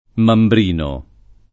Mambrino [ mambr & no ]